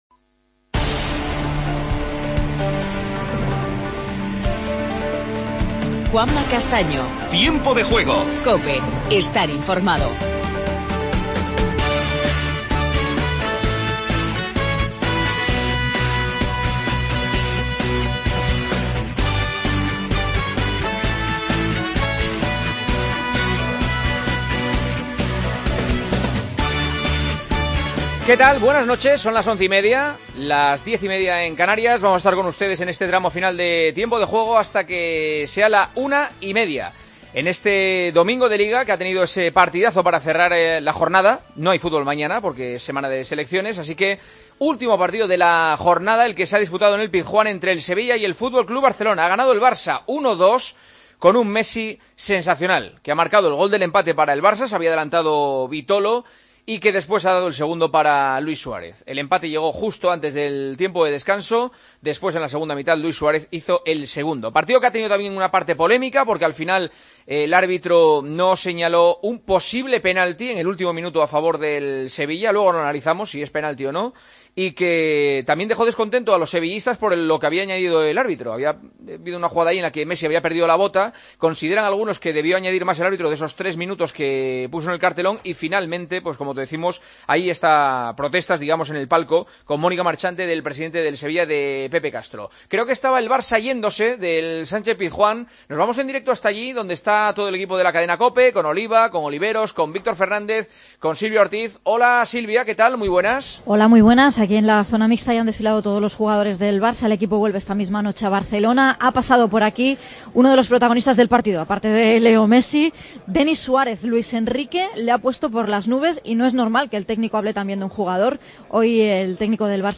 El Barcelona ganó 1-2 al Sevilla en la 11ª jornada. Escuchamos a Denis Suárez y entrevistamos a Sarabia. El Real Madrid derrotó 3-0 al Leganés y anunció la renovación de Cristiano Ronaldo. Conocemos la actualidad del Atlético de Madrid tras la derrota en Anoeta.